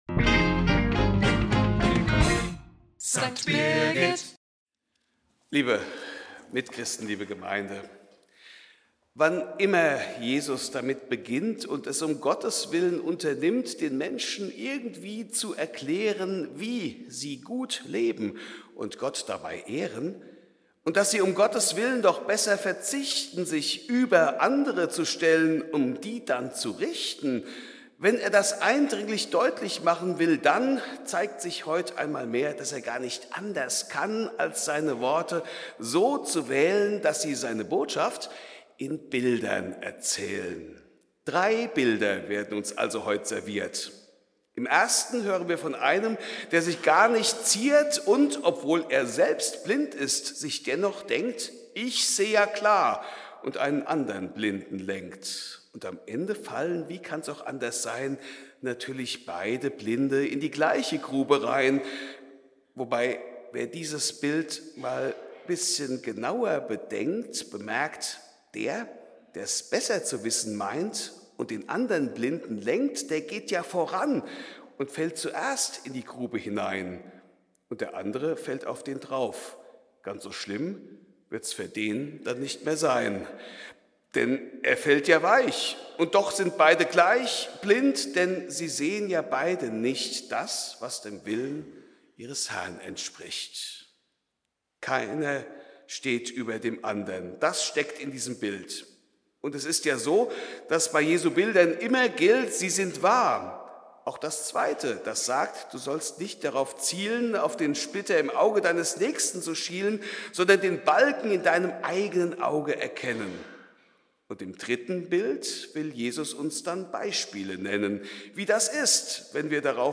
Fastnachtspredigt